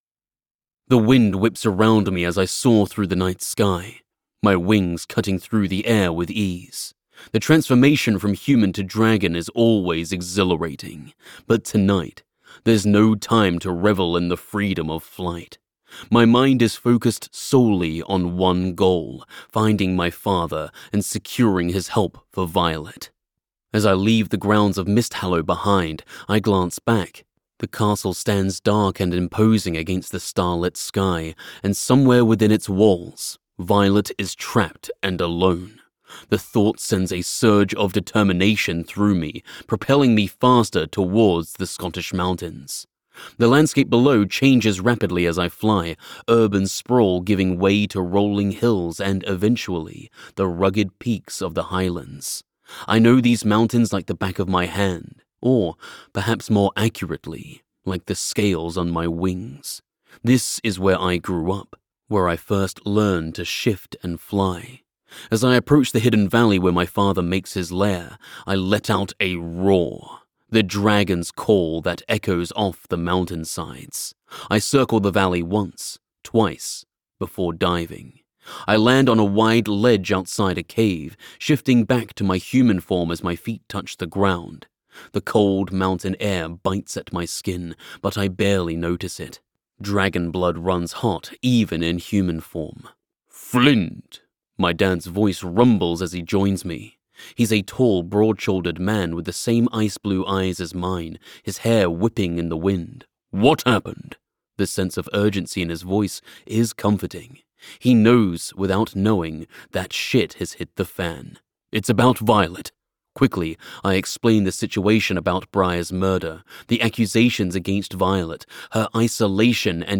A confident baritone–low tenor range, with natural authority and a smooth, controlled warmth that reads immediately professional.
Narration
Uk British Powerful Engaging